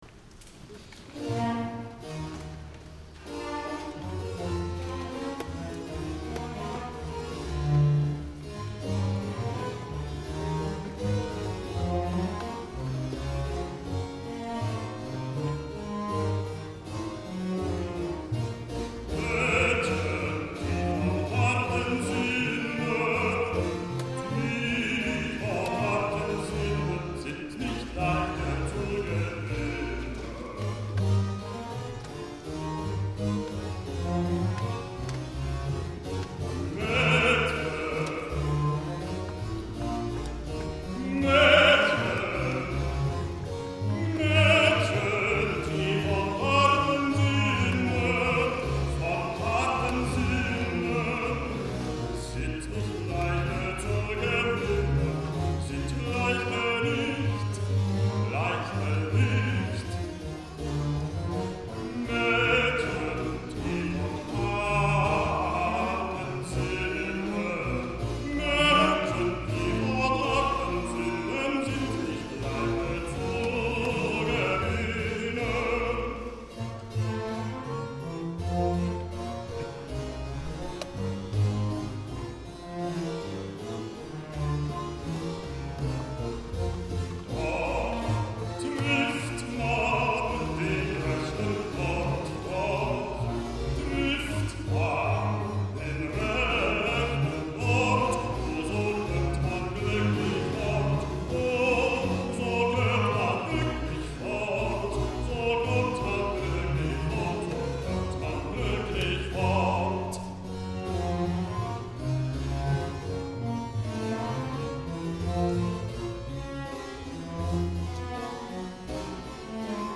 Recorded live in concert at Grace Cathedral, San Fransisco, California